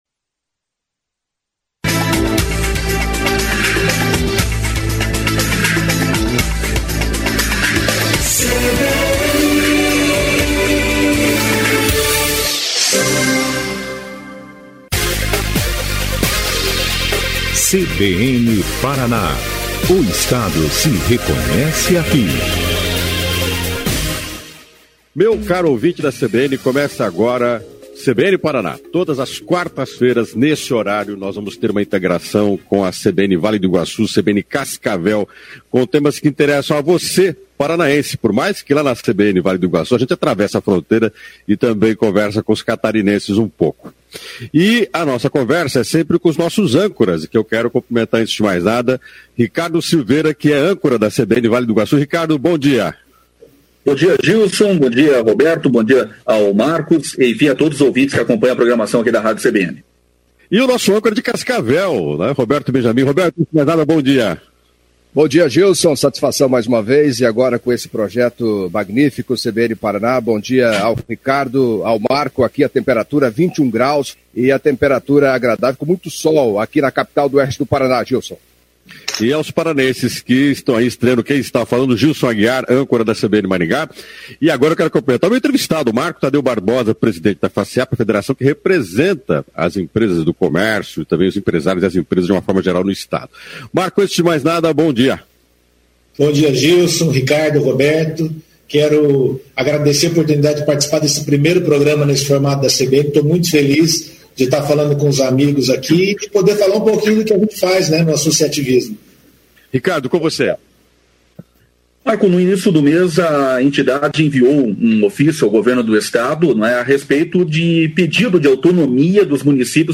Participação das CBNs de Maringá, União da Vitória e Cascavel.